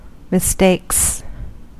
Ääntäminen
Ääntäminen US Haettu sana löytyi näillä lähdekielillä: englanti Käännöksiä ei löytynyt valitulle kohdekielelle. Mistakes on sanan mistake monikko.